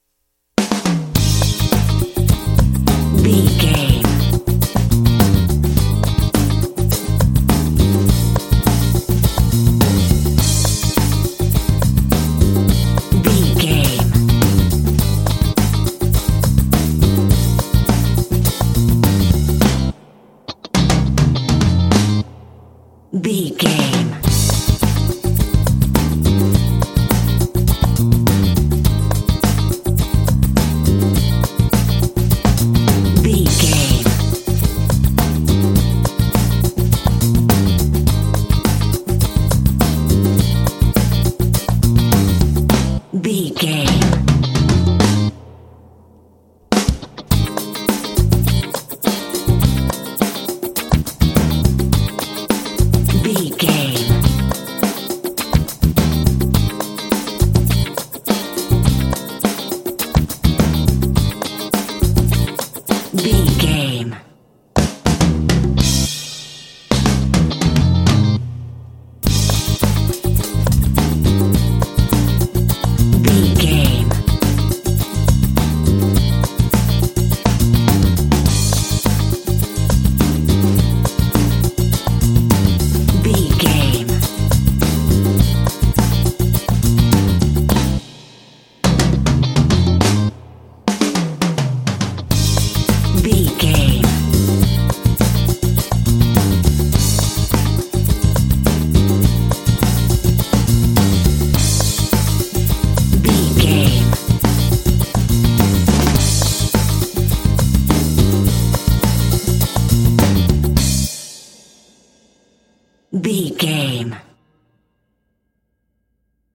Aeolian/Minor
groovy
driving
energetic
bass guitar
drums
electric guitar